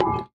foundry-clamp.ogg